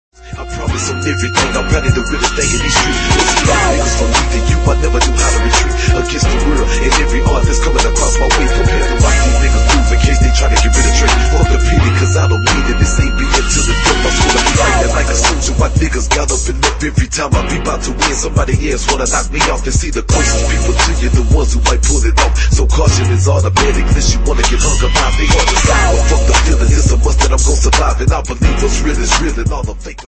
Rap & Hip Hop